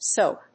/sop(米国英語), səʊp(英国英語)/